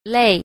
b. 類 – lèi – loại